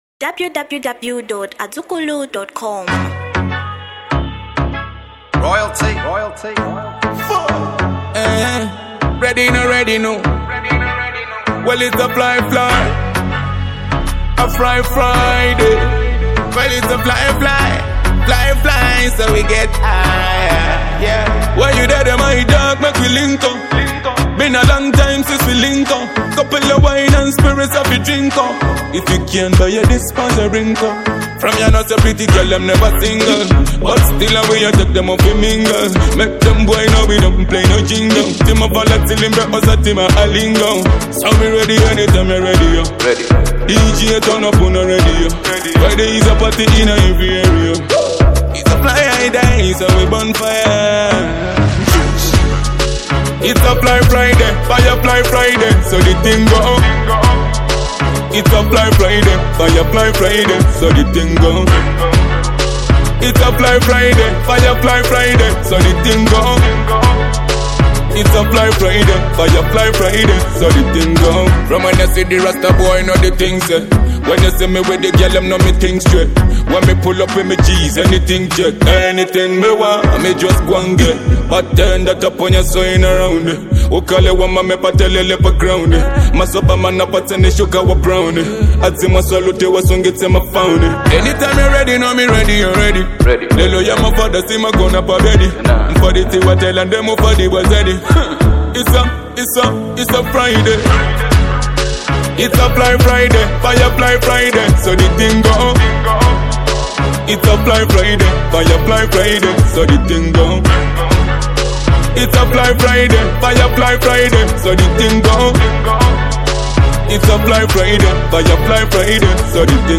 Genre Reggae & Dancehall